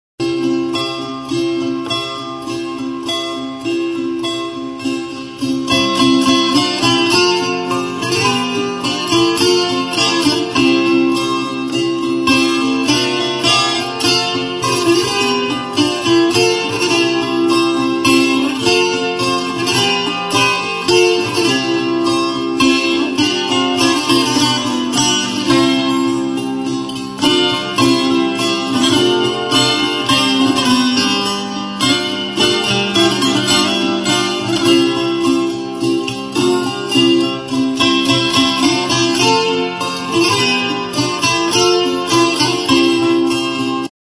Music instrumentsVIOLA BRAGUESA
Stringed -> Plucked